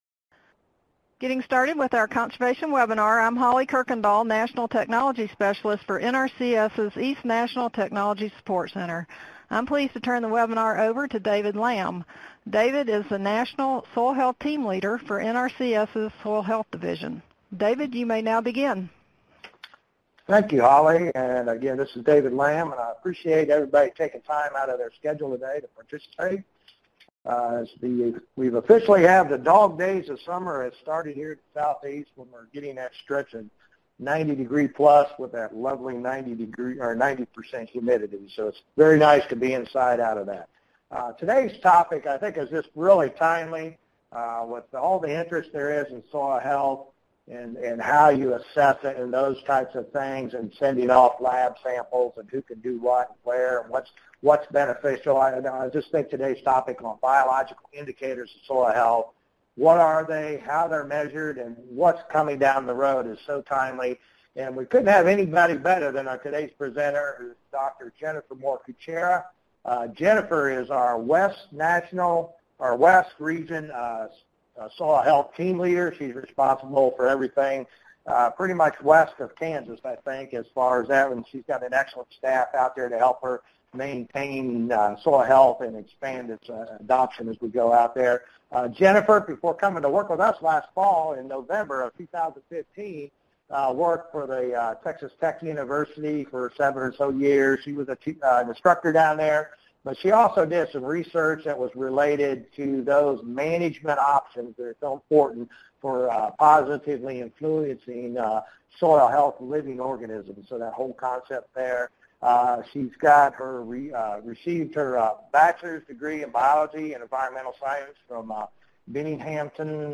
Streaming Podcast Webinar Audio - CEUs and certificates are not available for podcasts.